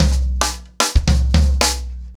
TimeToRun-110BPM.43.wav